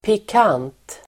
Uttal: [pik'an:t]